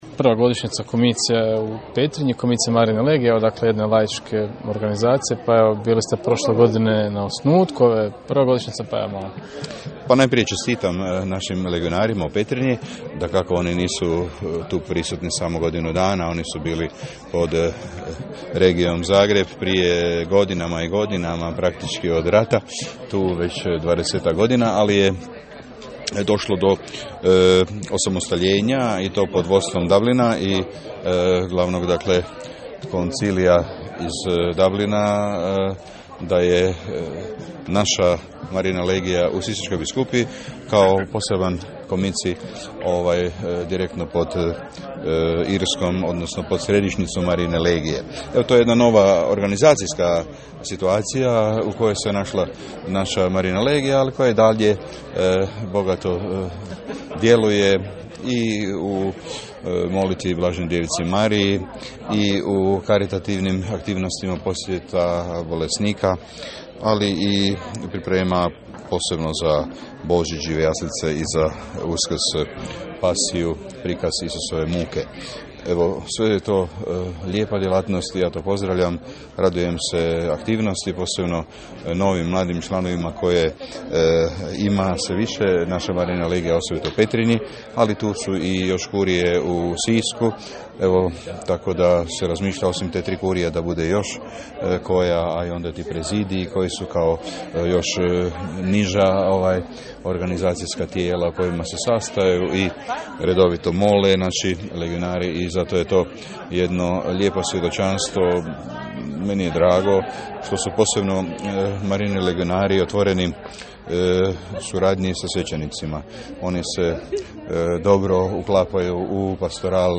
Nakon misnog slavlja kratko smo porazgovarali s biskupom koji nam je rekao nekoliko prigodnih rije?i:
BISKUP.KOSIC_.mp3